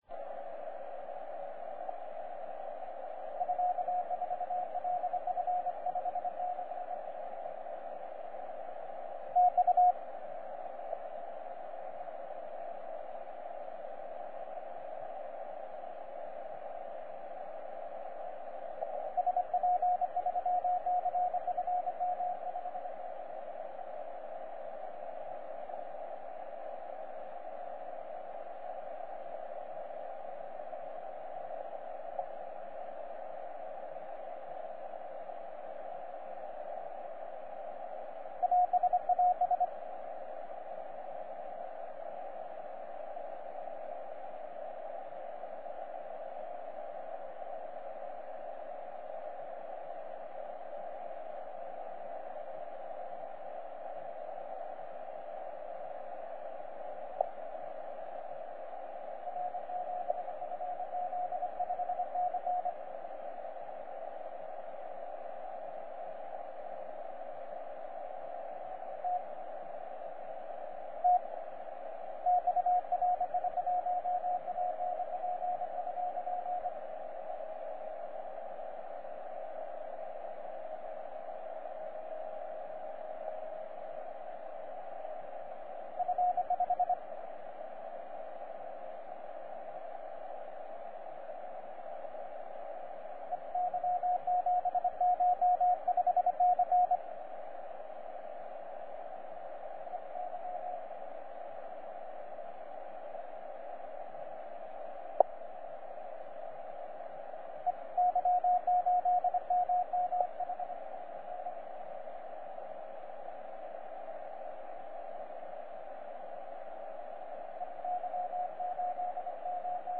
RI1ANP 10CW